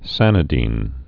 (sănĭ-dēn, -dĭn)